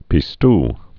(pē-st)